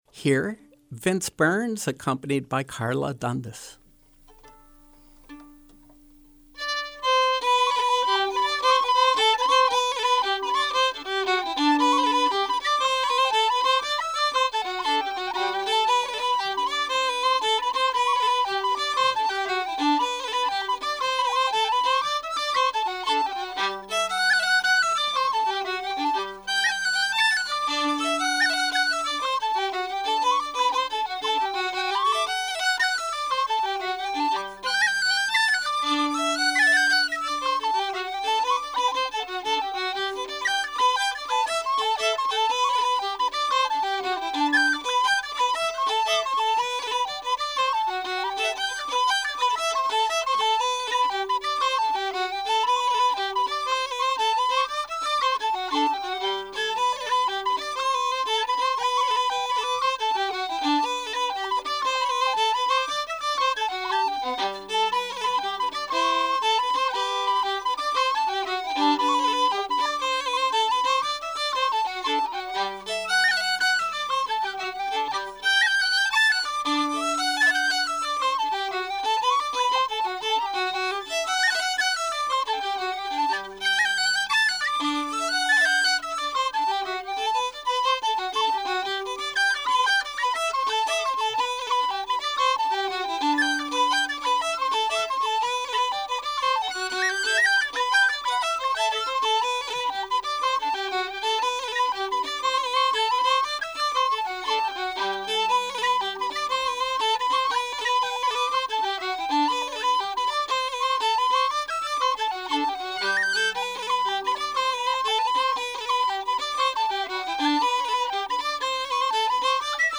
Live Music
Traditional Irish fiddler
whistle player